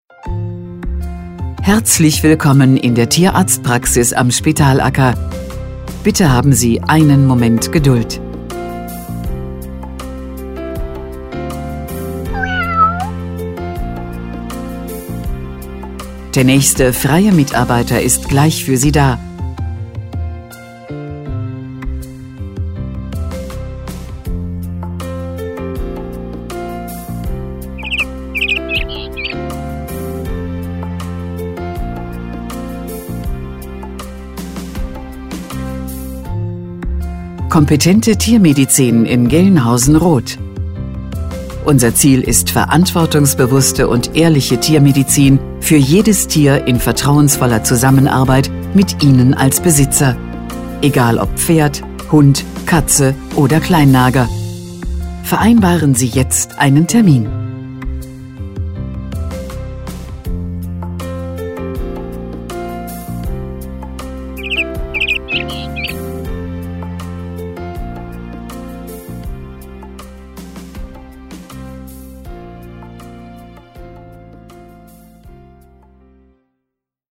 Telefonansage Tierarzt
Ansage vor Melden
Warteschleife